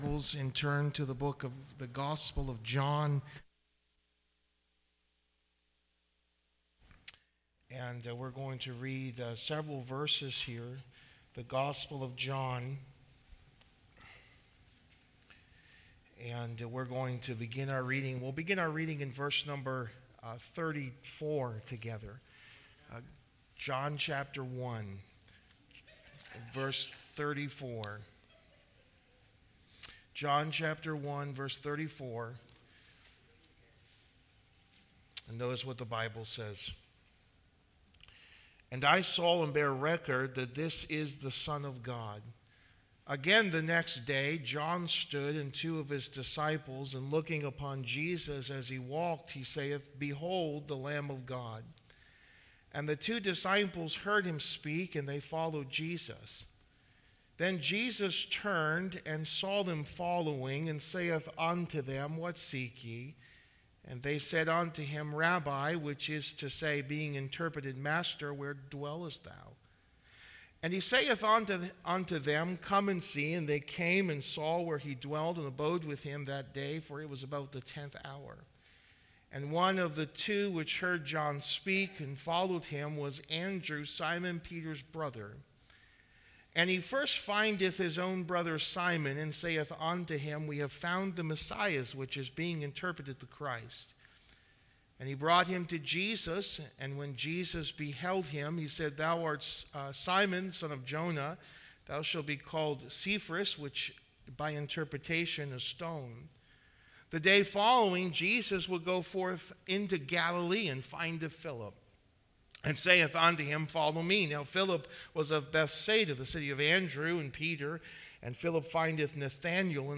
Real-Life Issues: The Testimony of Andrew, Philip and Nathanael | Sermons